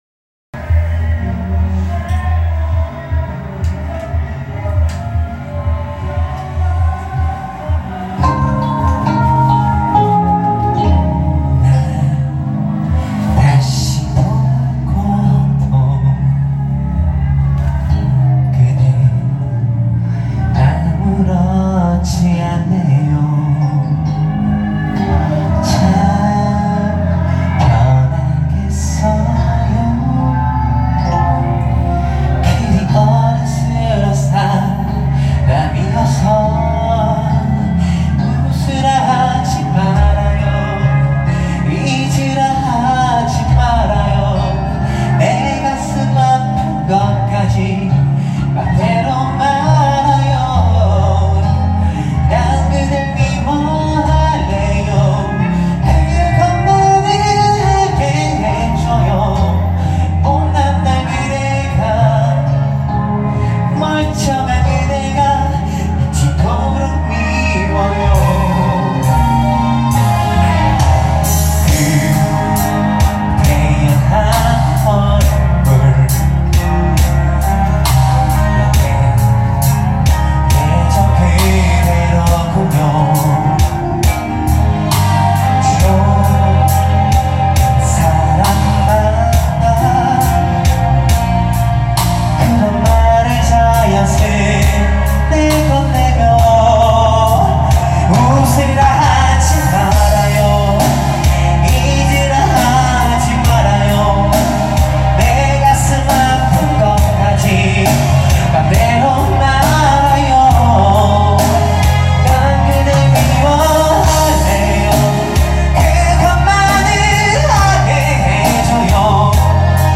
얼마전 혼자 코인노래방가서
녹음한 노래입니다.
음질은 정식녹음이 아닌
그냥 동영상촬영을